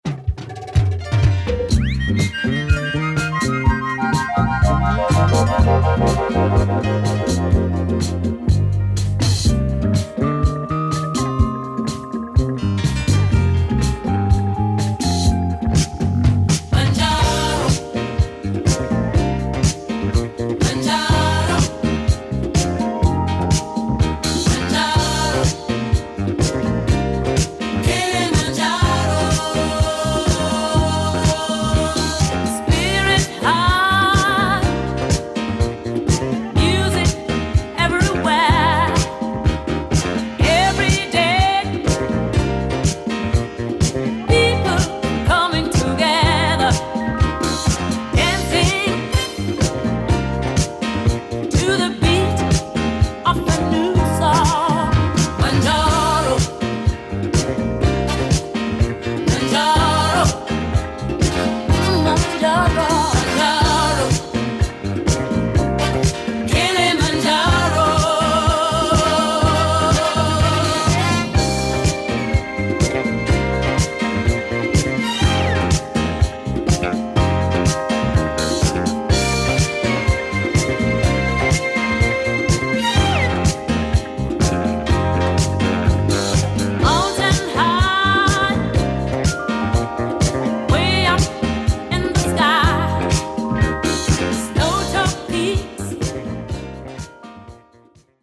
Including the monster (and in demand) disco funk tune